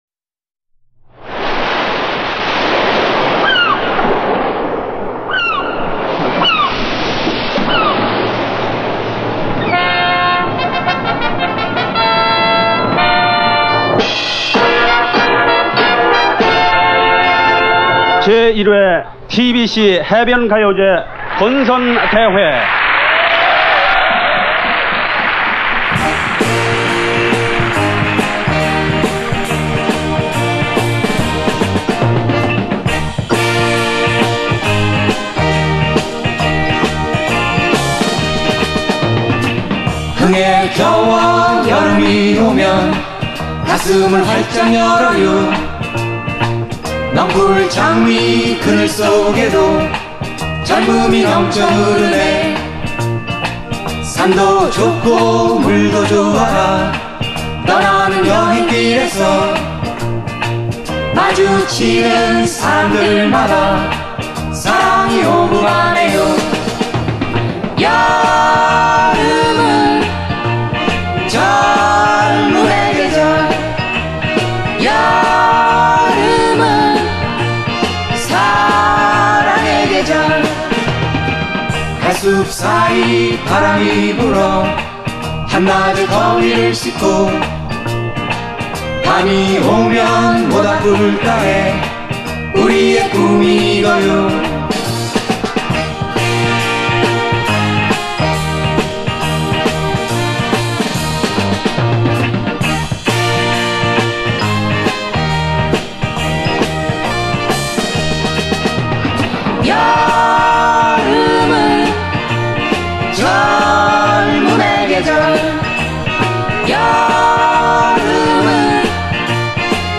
이대회의 특징은 캠퍼스 밴드들(그룹사운드)의 강세가 유난히 눈에 뛴다는 점이다.